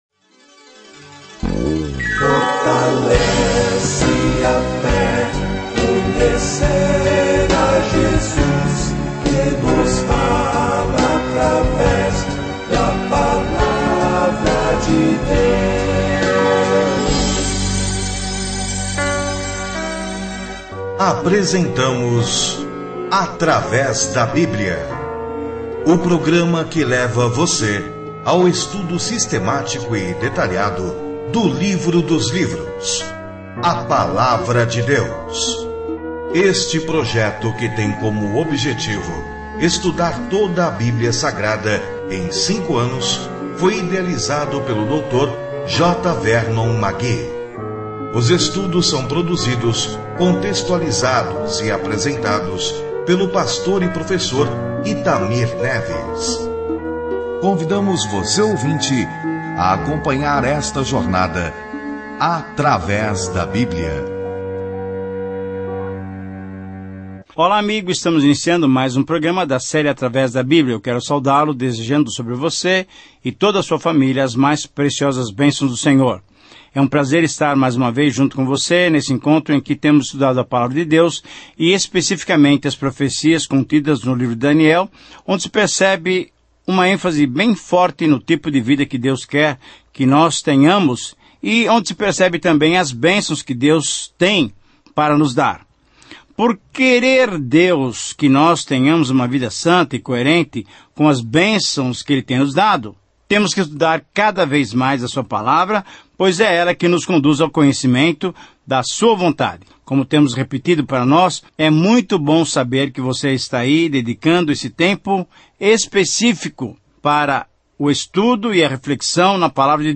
As Escrituras Daniel 10:1-20-21 Dia 16 Começar esse Plano Dia 18 Sobre este Plano O livro de Daniel é tanto uma biografia de um homem que acreditou em Deus quanto uma visão profética de quem eventualmente governará o mundo. Viaje diariamente por Daniel enquanto ouve o estudo em áudio e lê versículos selecionados da palavra de Deus.